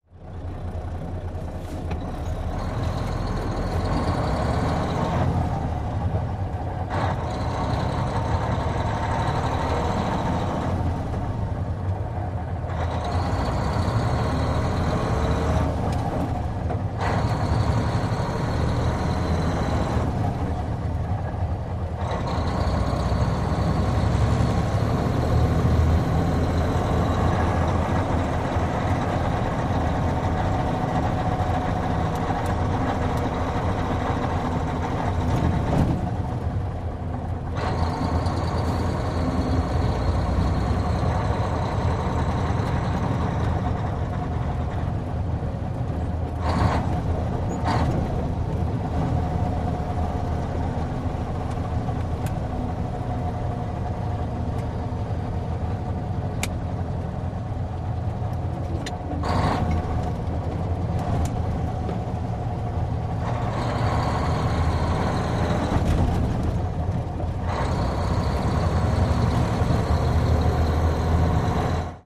tr_macktruck_driving_02_hpx
Mack truck interior point of view of driving and shifting gears. Vehicles, Truck Engine, Motor